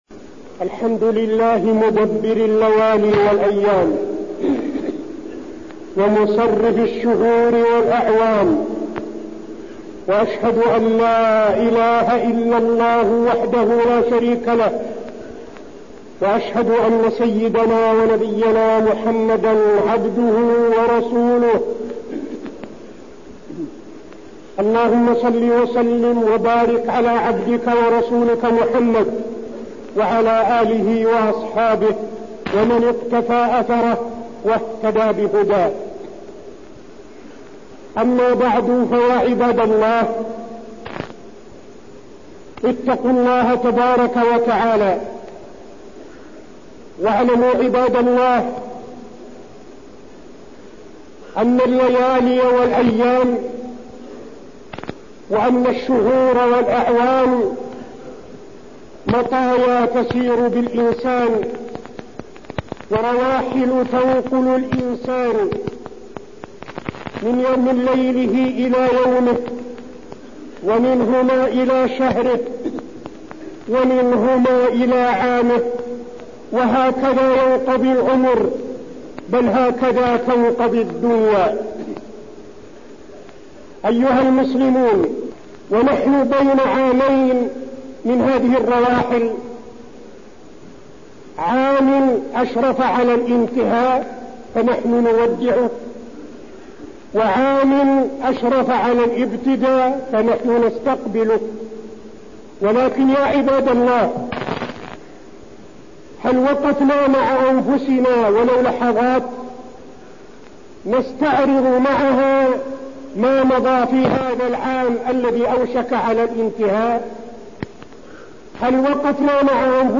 تاريخ النشر ٢٨ ذو الحجة ١٤٠٢ هـ المكان: المسجد النبوي الشيخ: فضيلة الشيخ عبدالعزيز بن صالح فضيلة الشيخ عبدالعزيز بن صالح الهجرة النبوية